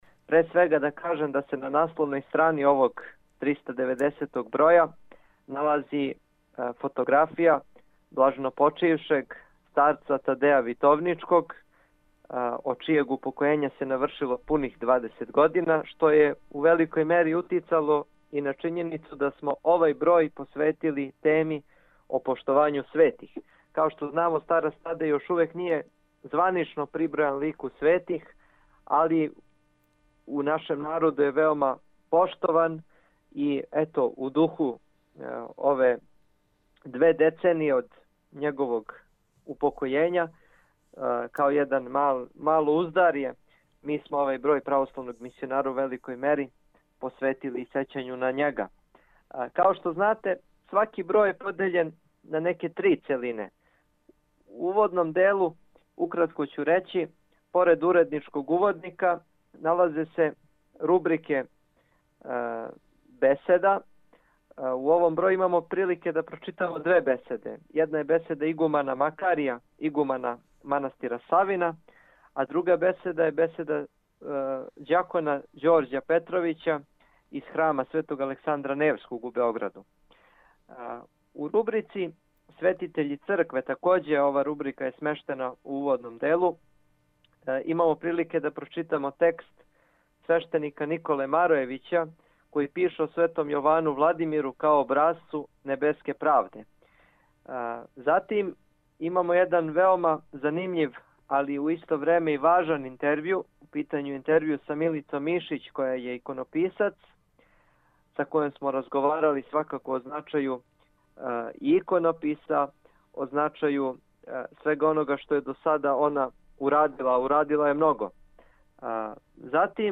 Звучни запис разговора